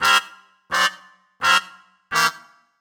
GS_MuteHorn_85-C.wav